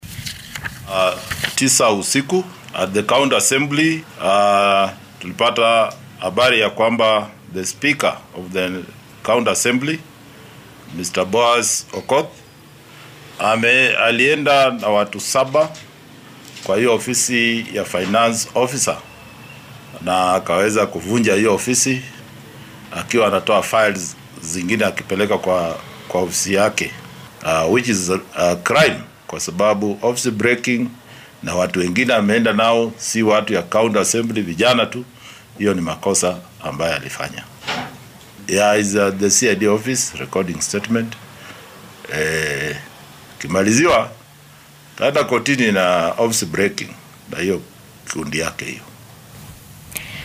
Waxaa arrintan warbaahinta u xaqiijiyay taliyaha booliska ee Migori Mark Wanjala.
Taliyaha-booliska-Migori.mp3